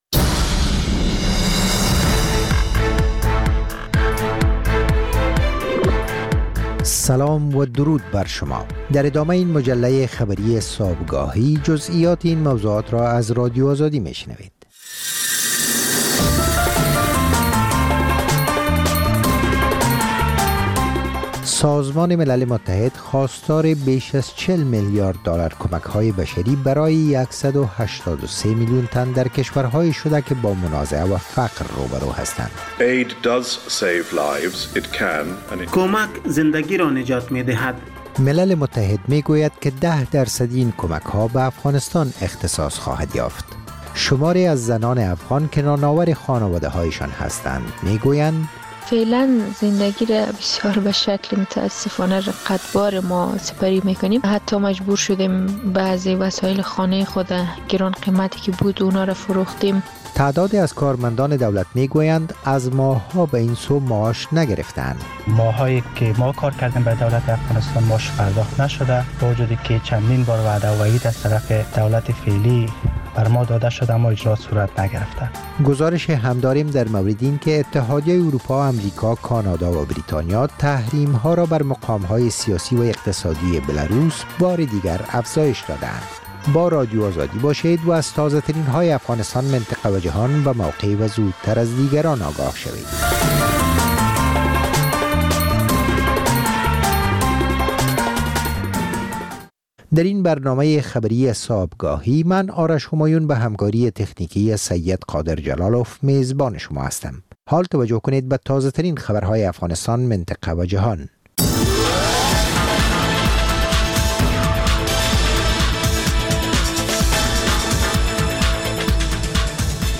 مجله خبری صبح‌گاهی